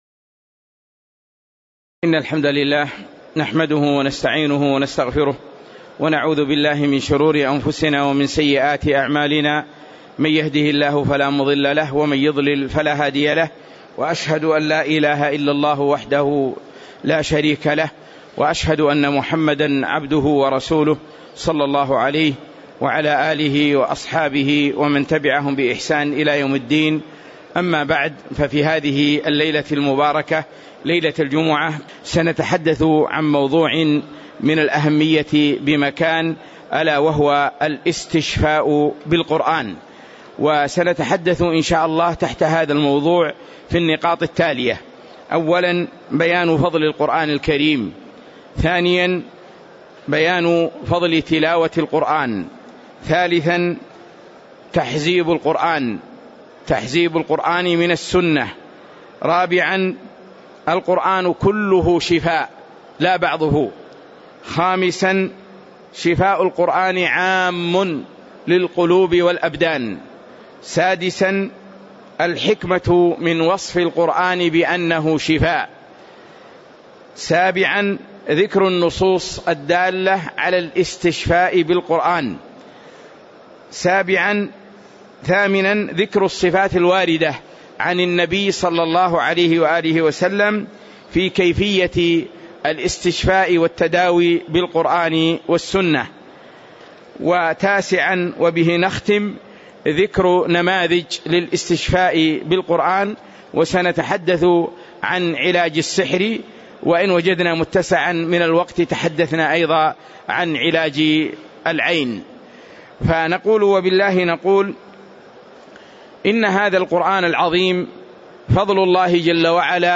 تاريخ النشر ١١ رمضان ١٤٤٠ هـ المكان: المسجد النبوي الشيخ